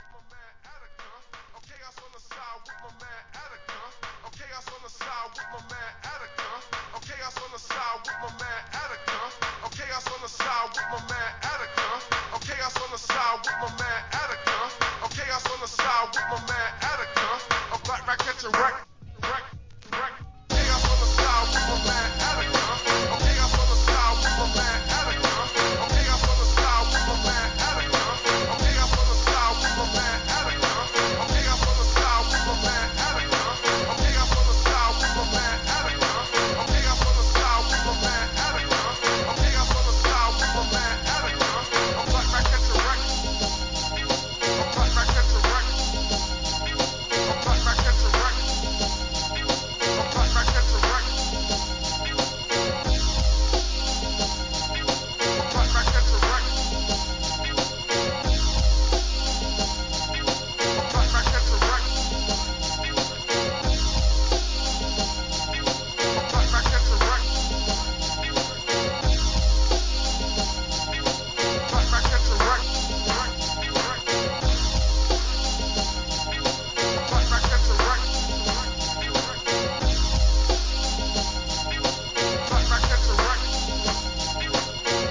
アブストラクト〜ヒップホップ・ブレイクビーツ!